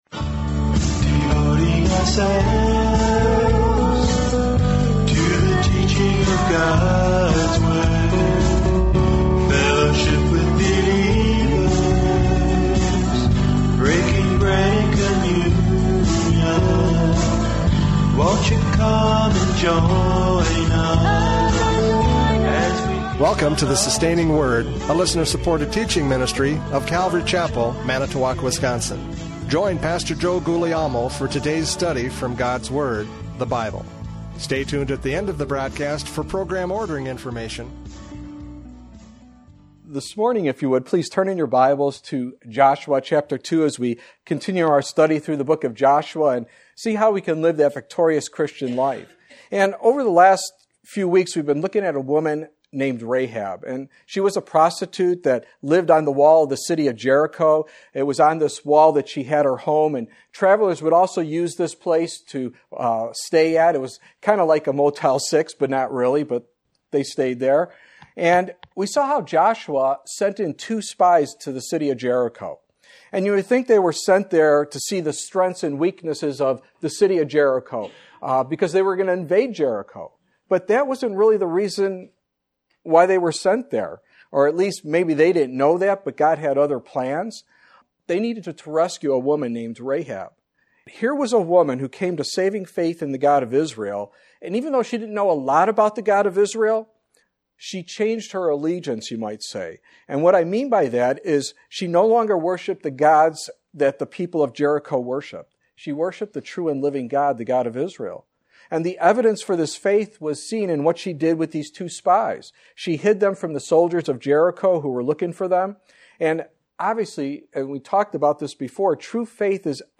Joshua 2:8-14 Service Type: Radio Programs « Joshua 2:2-7 The Faith of Rahab!